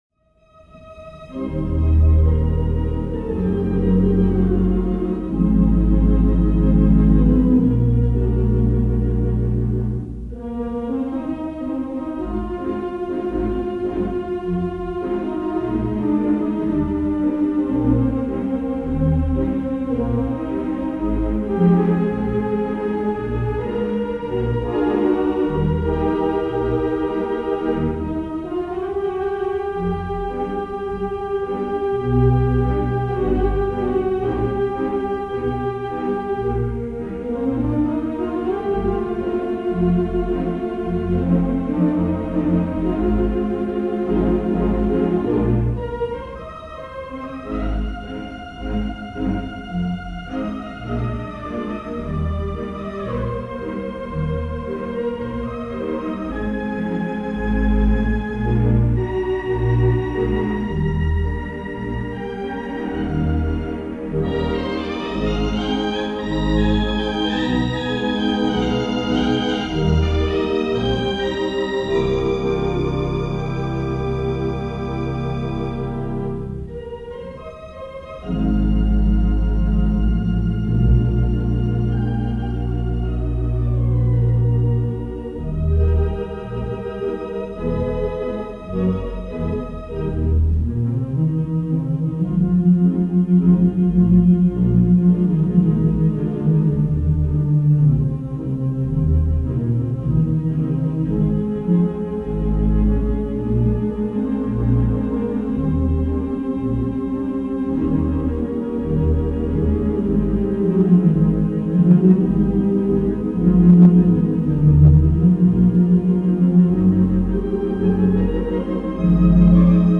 2/11 Mighty WurliTzer Theatre Pipe Organ
Lafayette Theatre, Suffurn, New York